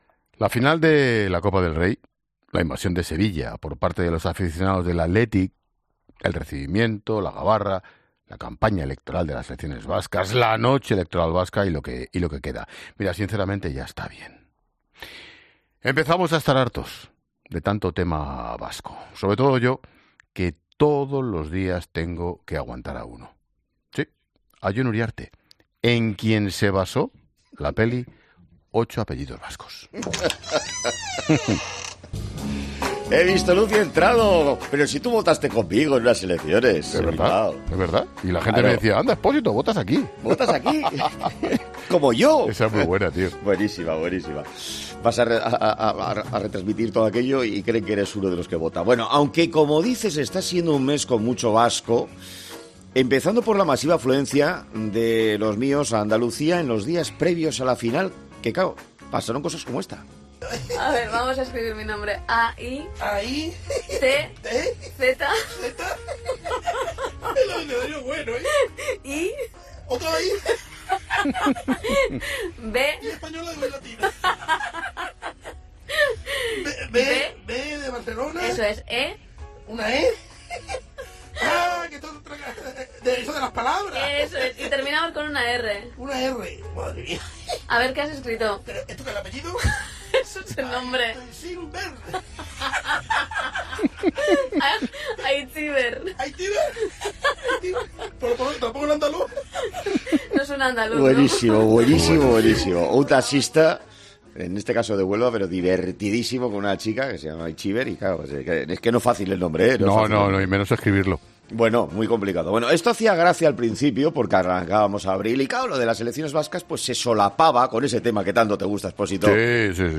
Ambos repasan, con mucho humor y anécdotas, cómo ha transcurrido la noche electoral en el País Vasco y el día de después